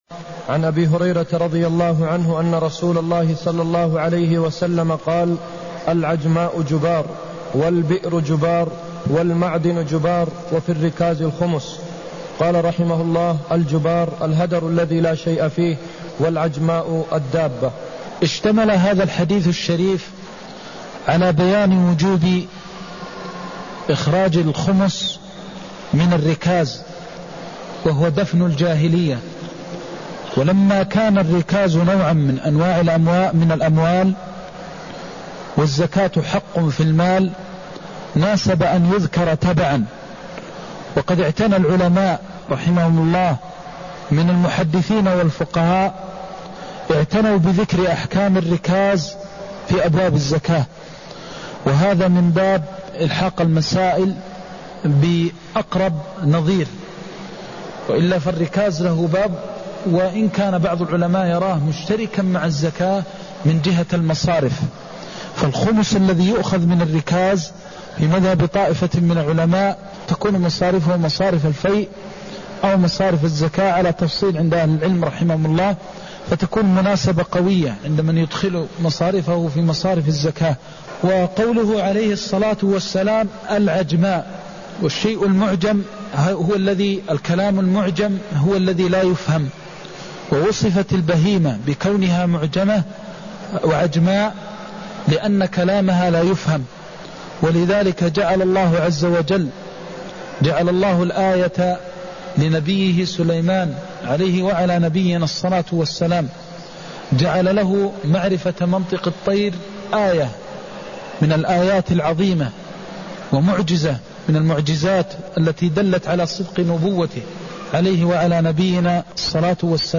المكان: المسجد النبوي الشيخ: فضيلة الشيخ د. محمد بن محمد المختار فضيلة الشيخ د. محمد بن محمد المختار وفي الركاز الخمس (165)